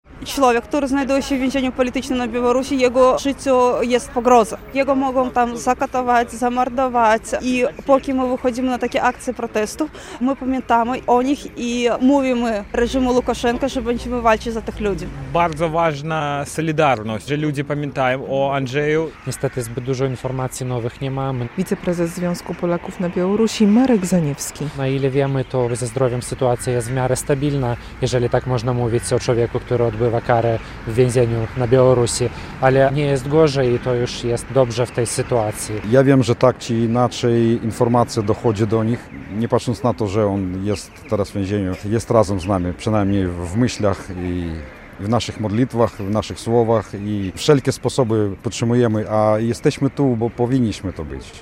Akcja solidarności z Andrzejem Poczobutem - relacja